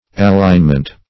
Allignment \Al*lign"ment\, n.
allignment.mp3